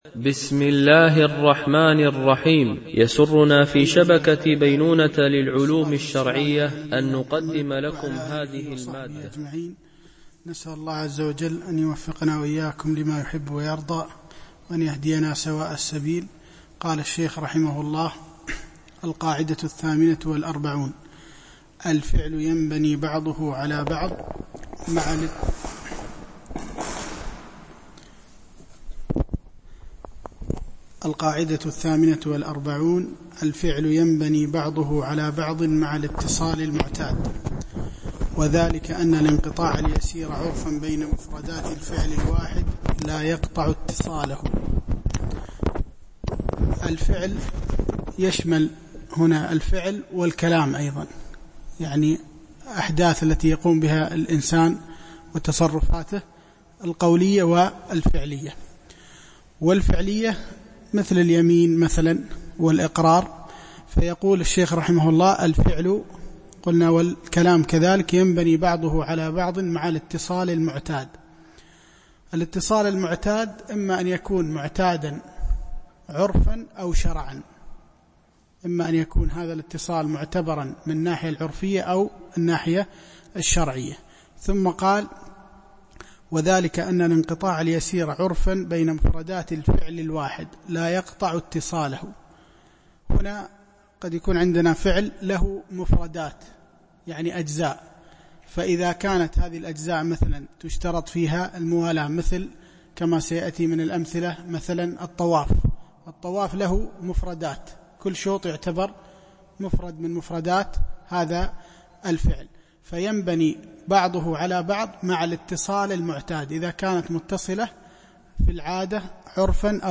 شرح القواعد والأصول الجامعة والفروق والتقاسيم البديعة النافعة - الدرس 18 ( القاعدة 48-54)